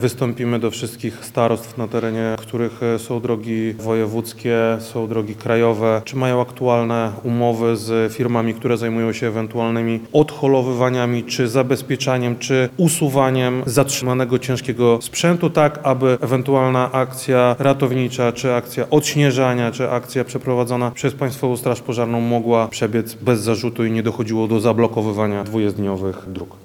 - Nie dopuścimy do tego, by pojazdy zimowego utrzymania dróg utknęły i nie pracowały podczas dużych opadów śniegu i mrozów - zapewnił wojewoda lubelski,